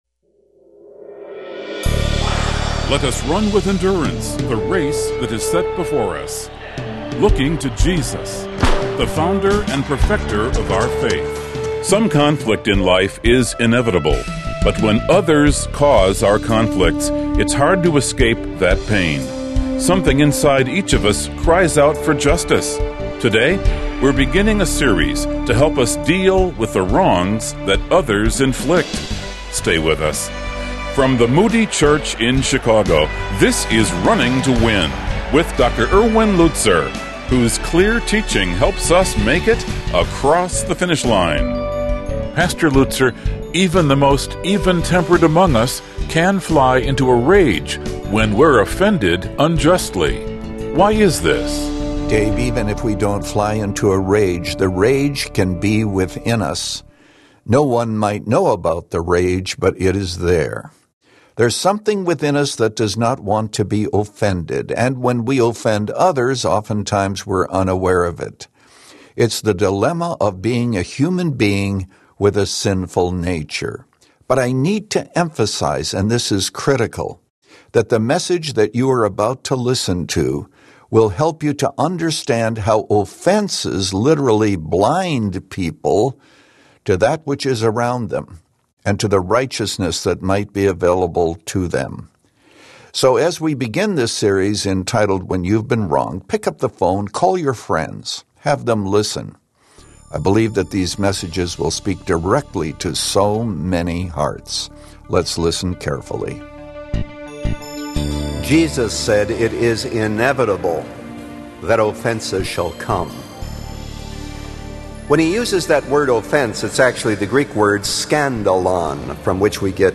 In this message from Romans 12